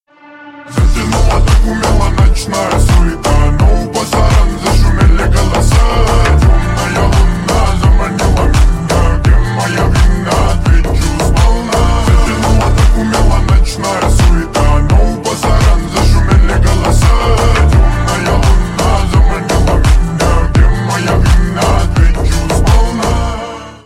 Ремикс # Slowed + Reverb
клубные # громкие